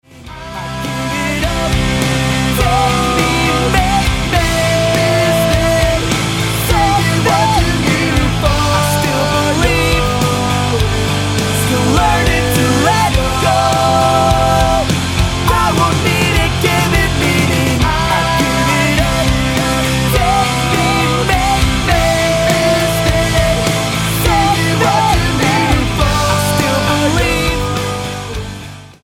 Punk band
Style: Rock